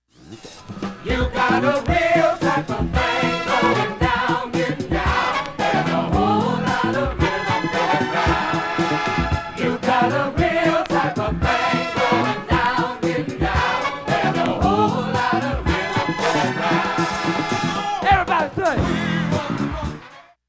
おもて面下部真ん中あたり かるいつぶれハゲ ◆盤質全面/EX+ 概況 下記も問題はありません、 音質良好全曲試聴済み。
瑕疵部分 D-2序盤にかすかなプツが９回出ます。 それだけ 現物の試聴（上記録音時間２０秒）できます。
素晴らしいPファンク最高傑作ライヴ盤２枚組